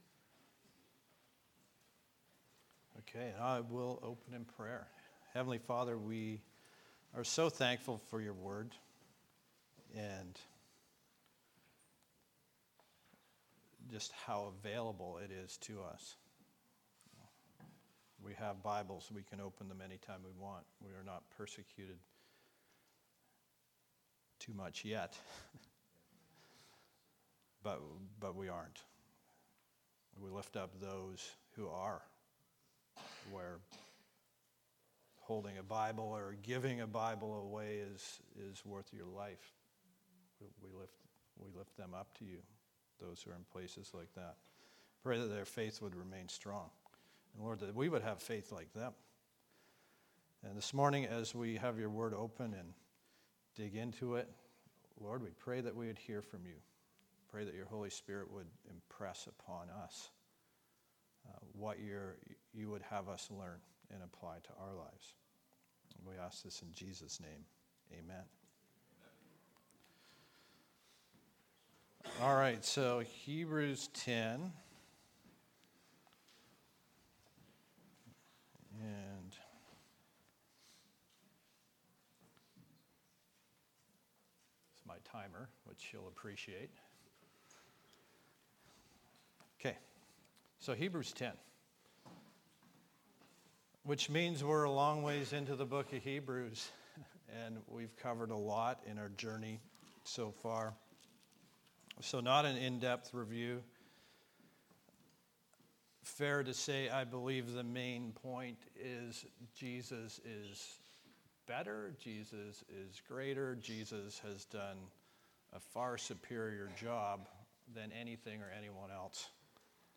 Sermons | Calvary Chapel Comox Valley